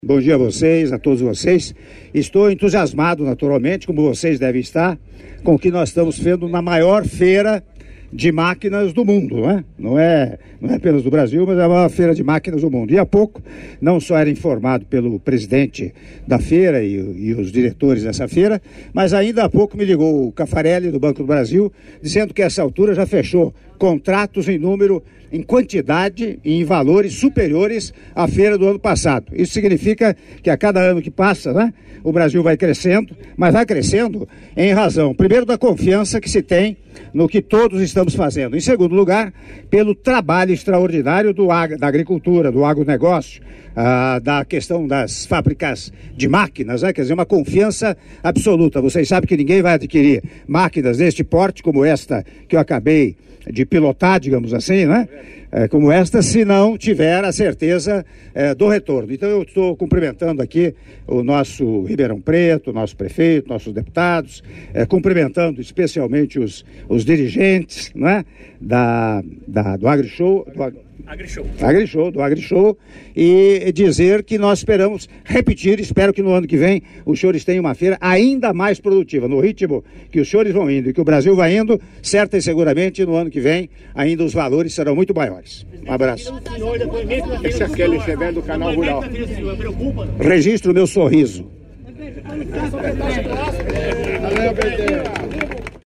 Áudio da entrevista coletiva concedida pelo Presidente da República, Michel Temer, após a visita à 25ª Edição da Feira Internacional de Tecnologia Agrícola em Ação (AGRISHOW) - Ribeirão Preto/SP- (01min35s)